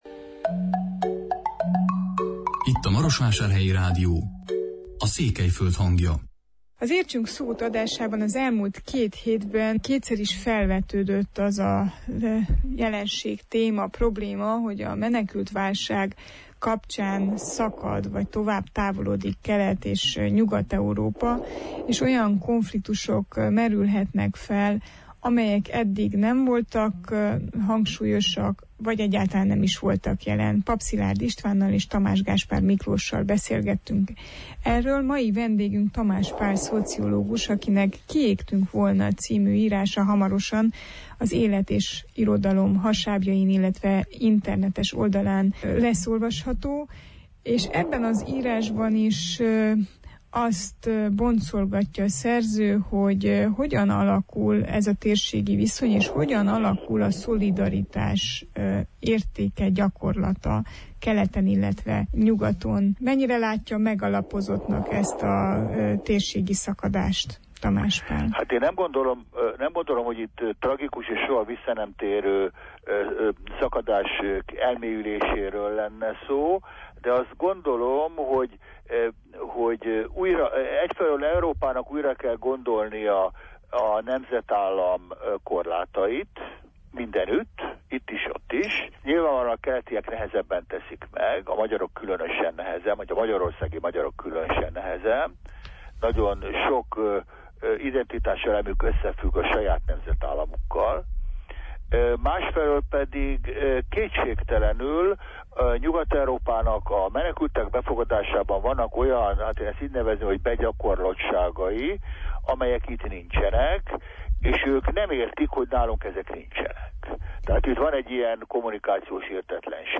Hogyan reagál a poszt-nemzetállam a menekült-válságra, törvényszerű-e a nemzetközösség gyengülése? Tamás Pál szociológussal beszélgettünk.